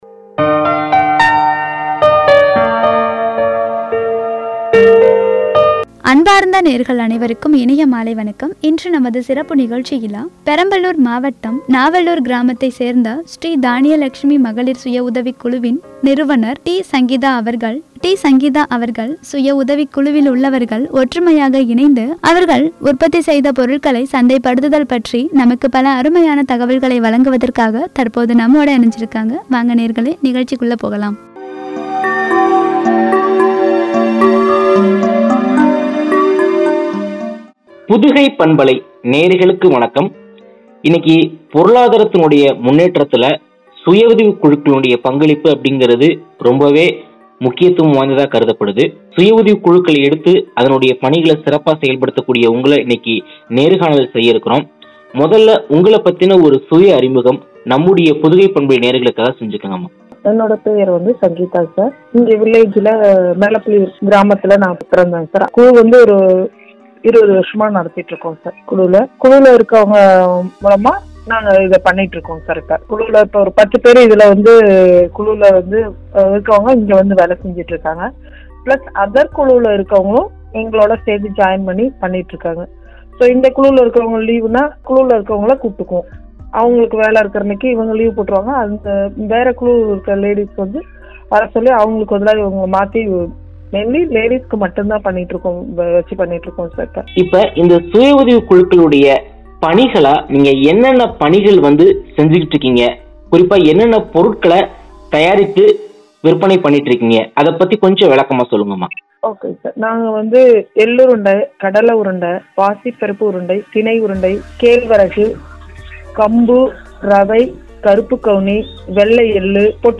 “சிறுதானிய சிற்றுண்டிகள்” என்ற தலைப்பில் வழங்கிய உரையாடல்.